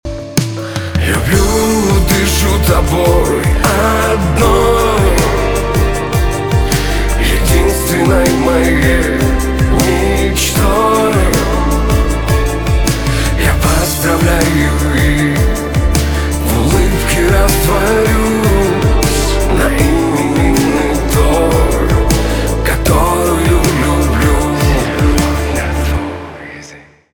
поп
гитара